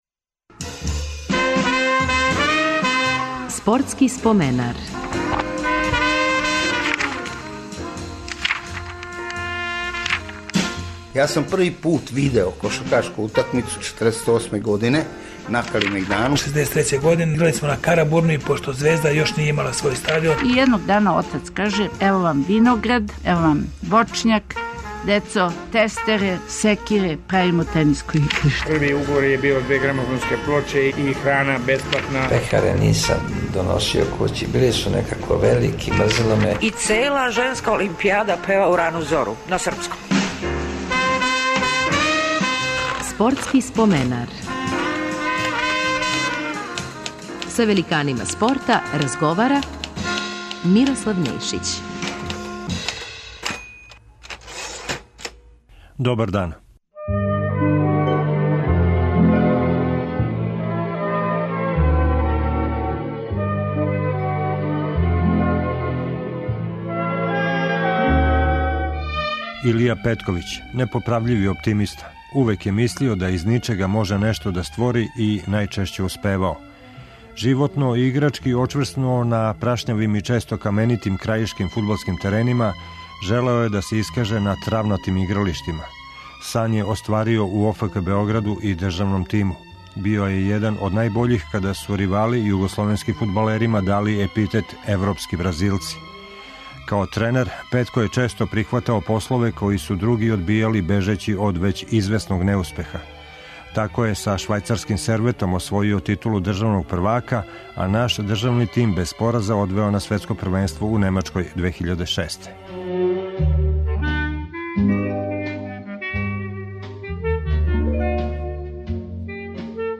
Почињемо нову серију разговора с асовима нашег спорта - гост ће бити фудбалер Илија Петковић. У време када су фудбалери с југословенских простора добили епитет европски Бразилци, Илија Петковић је био један од носилаца атрактивне и ефикасне игре нашег државног тима.